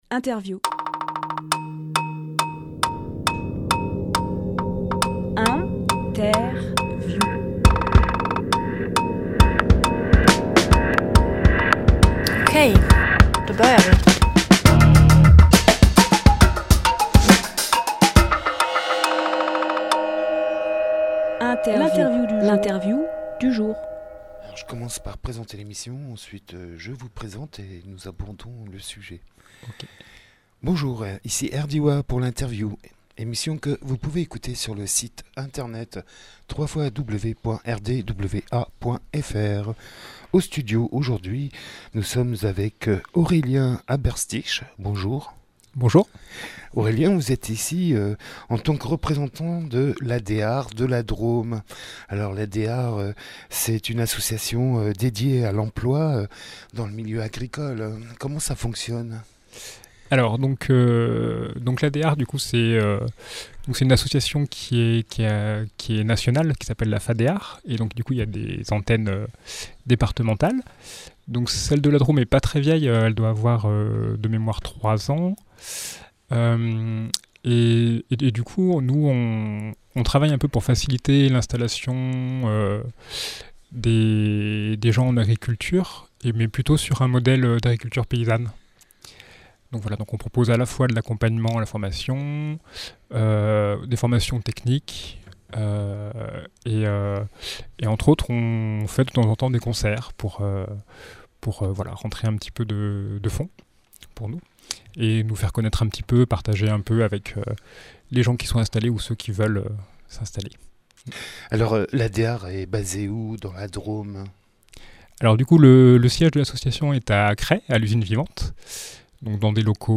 Emission - Interview Concert Paysan de l’Adear au Tas de fumier Publié le 27 juillet 2018 Partager sur…
Lieu : Studio RDWA